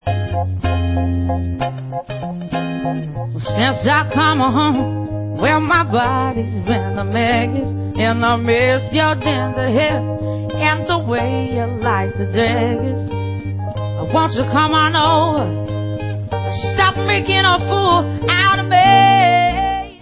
Live Lounge 2007
Pop